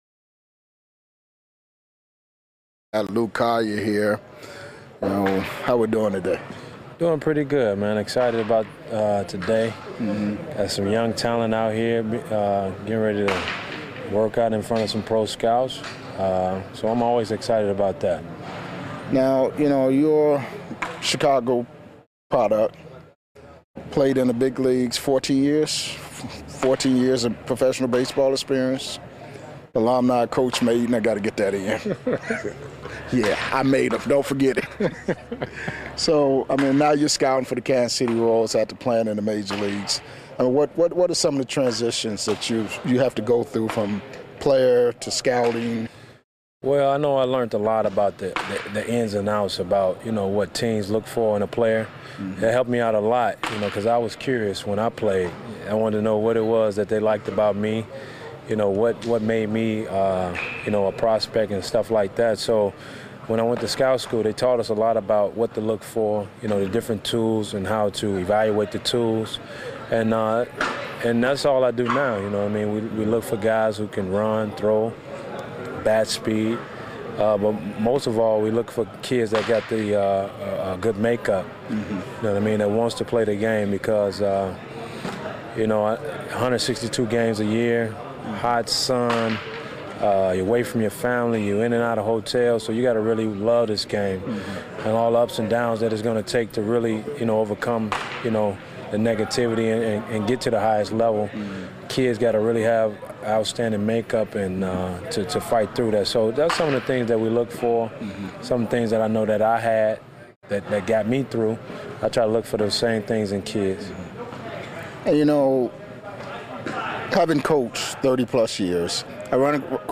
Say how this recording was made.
full uncut and unedited MLB interviews with past and present players